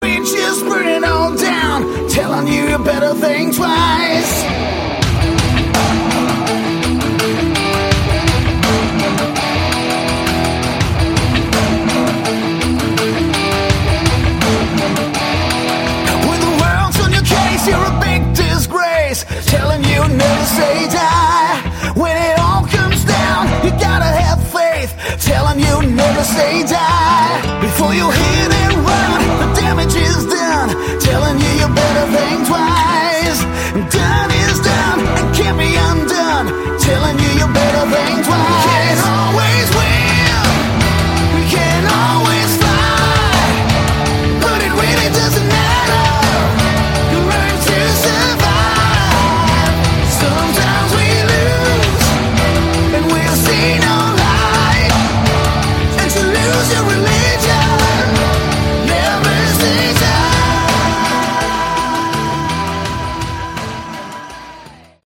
Category: AOR
drums, bass, keyboards, lead and acoustic guitar, vocals